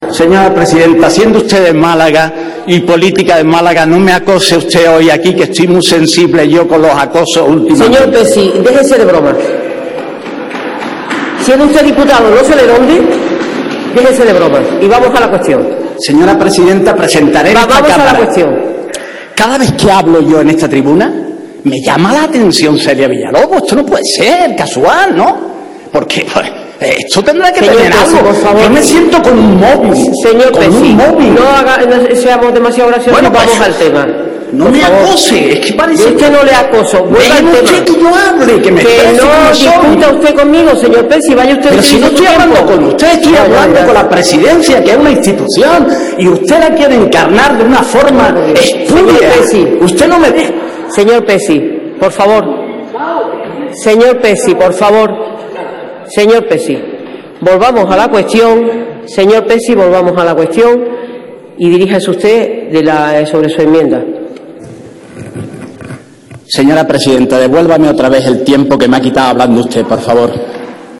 Manuel Pezzi vs Celia Villalobos en el pleno del 29/10/2013